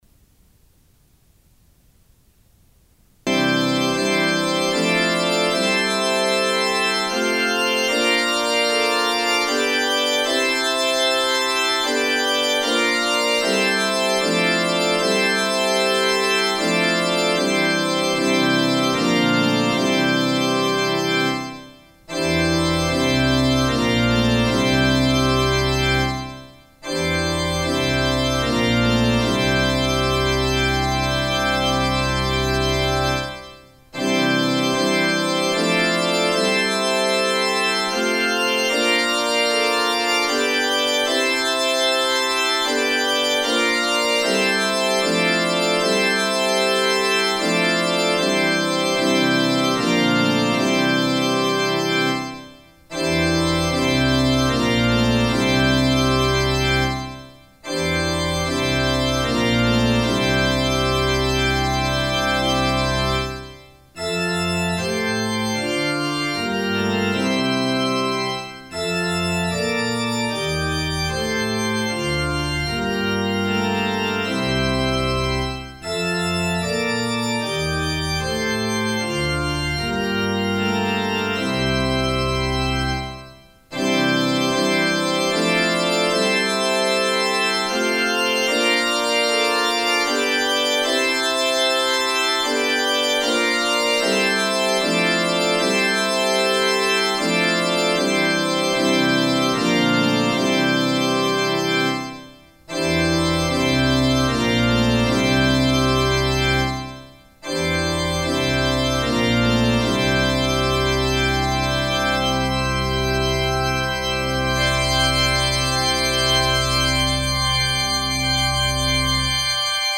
for piano (organ)